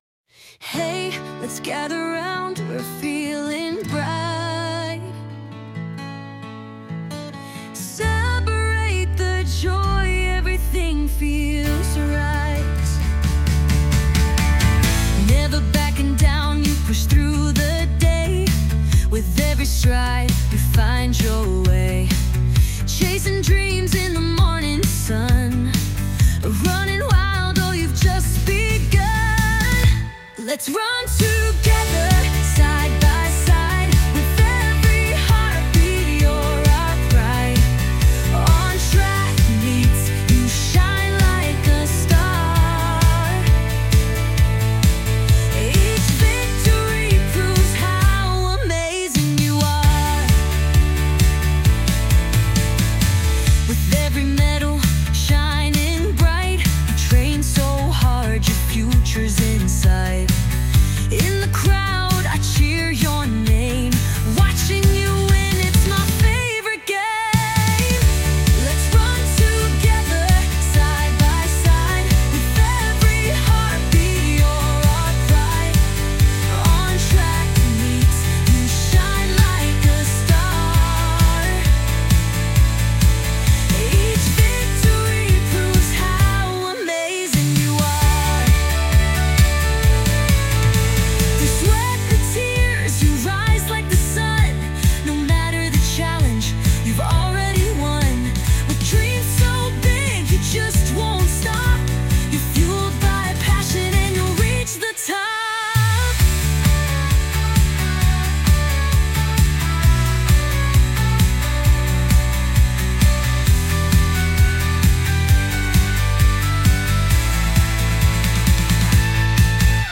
optimistic and upbeat melodies